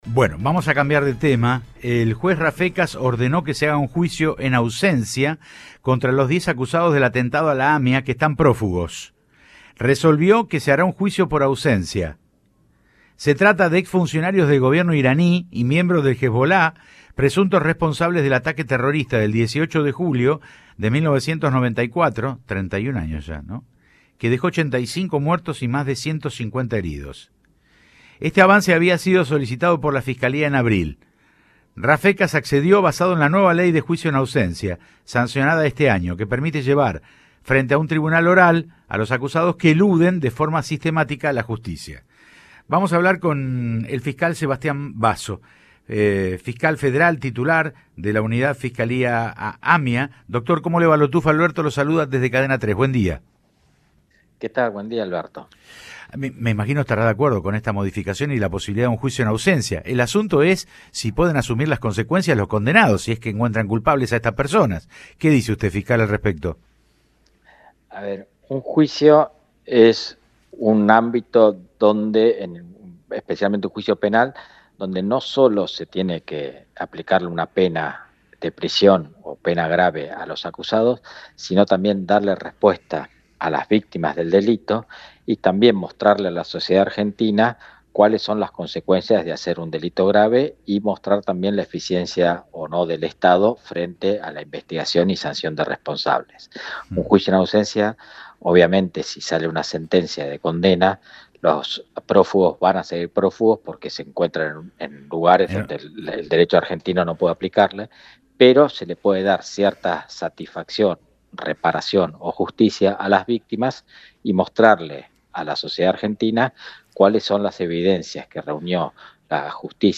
Sebastián Basso habló en Cadena 3 Rosario y dijo que con el juicio en ausencia contra los acusados buscan mostrar a la sociedad "las consecuencias de cometer un delito grave".